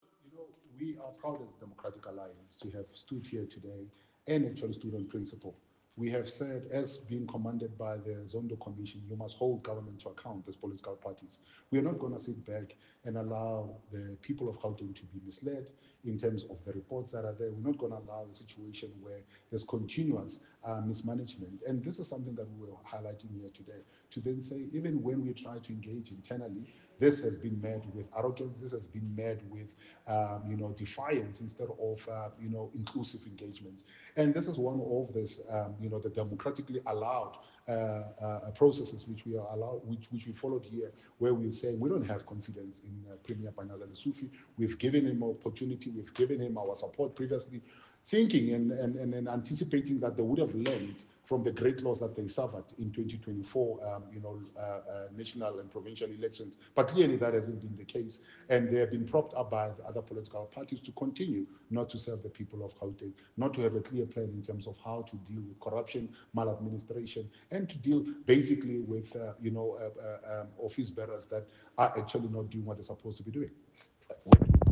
soundbite by Solly Msimanga MPL.